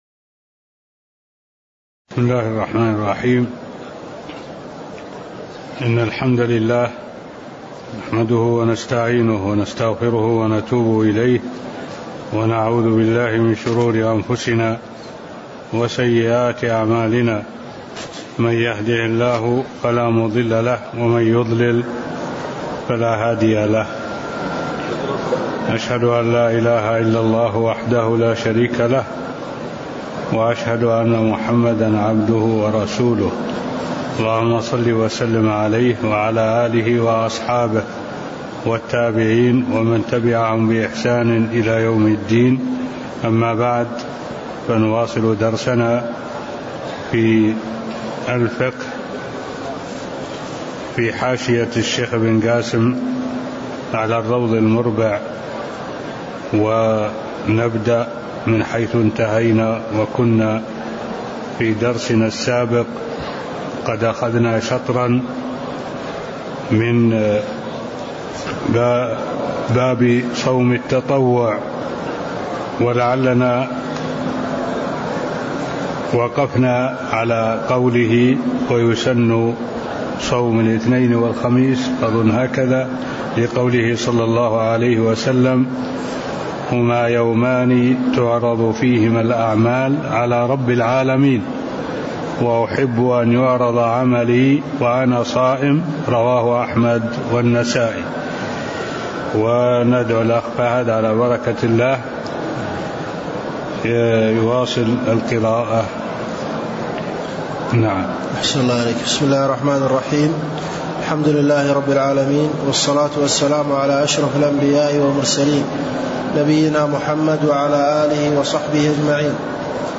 المكان: المسجد النبوي الشيخ: معالي الشيخ الدكتور صالح بن عبد الله العبود معالي الشيخ الدكتور صالح بن عبد الله العبود باب صوم التطوع (قول المصنف ويسن صوم يومي الأثنين والخميس) (02)